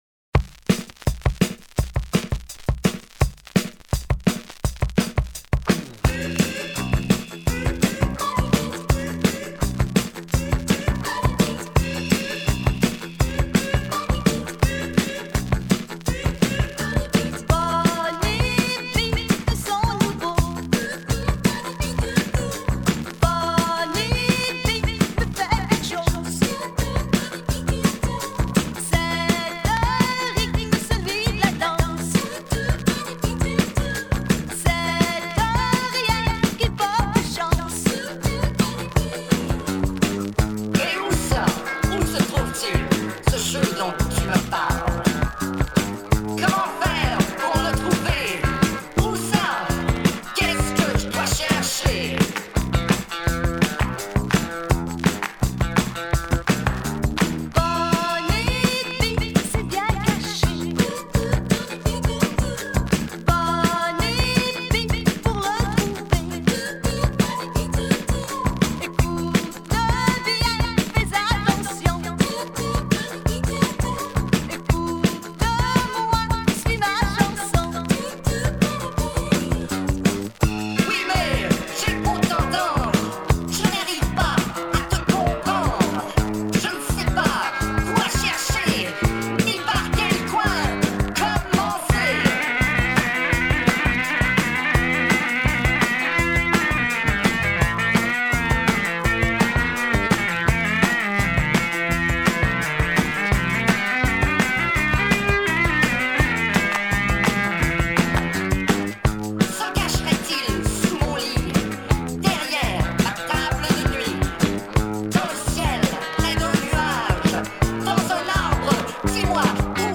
But at 45 RPM, it's a new wave treat!